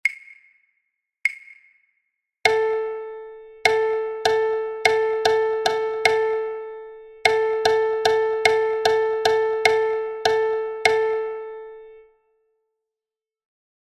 Look at the image and listen to the sound, which only shows a metronome rhythmic pattern to assimilate the special value group of a triplet in a binary meter with binary subdivision (2/4).